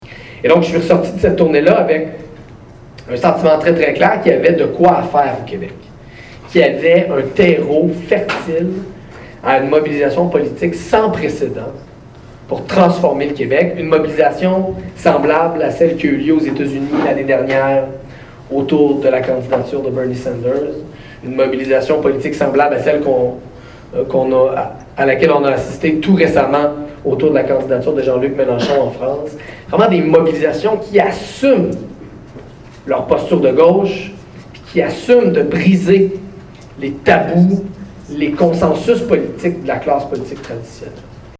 en entrevue avec Gabriel Nadeau-Dubois.